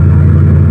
engine0.wav